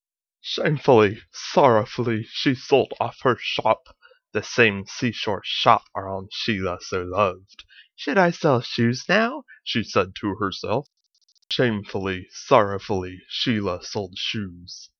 Practice (second part, I got the emotion in there at the start but it's not consistent! grr)